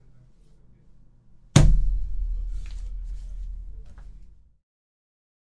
描述：一只手击打纸
标签： 打纸
声道立体声